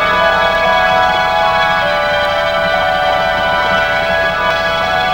453kb Dreamy looped tone used on new album